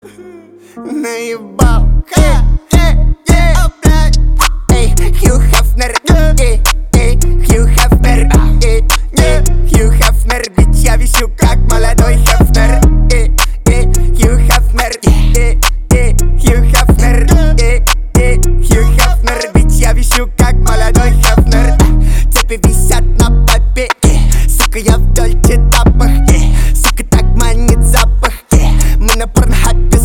• Качество: 320, Stereo
мощные басы
качающие
нецензурная лексика